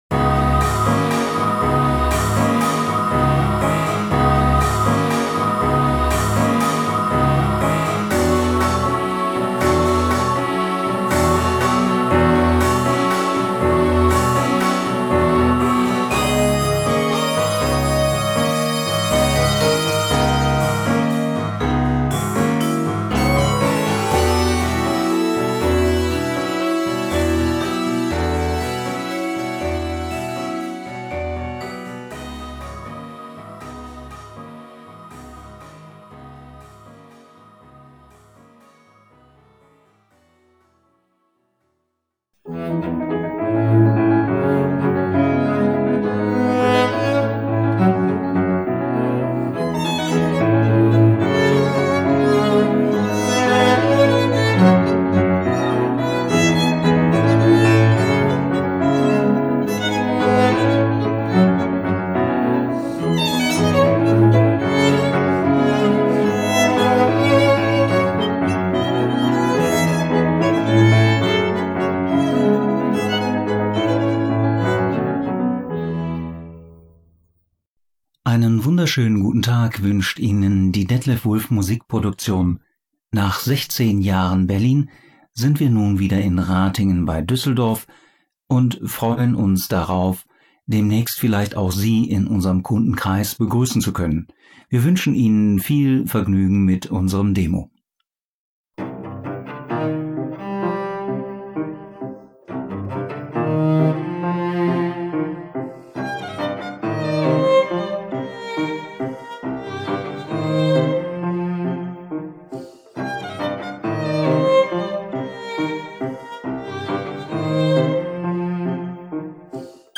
im eigenen Studio
Puppenspiel